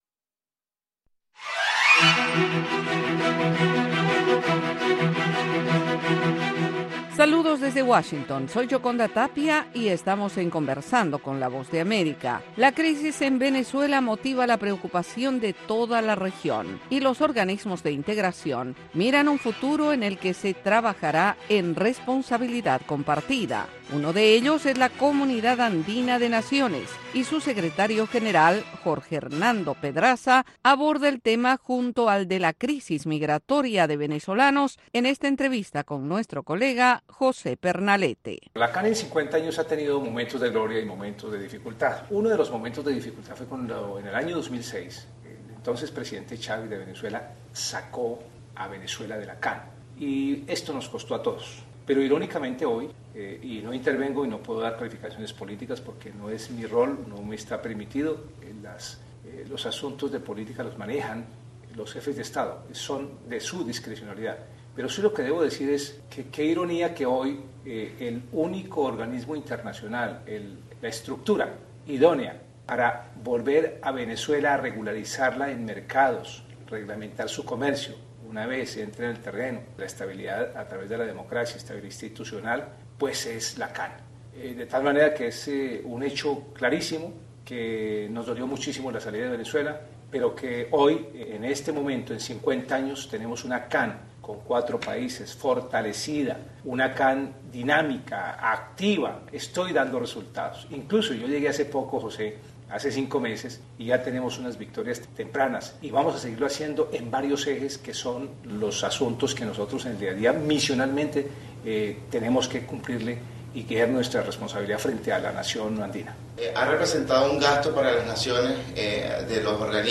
La Voz de América entrevista, en cinco minutos, a expertos en diversos temas.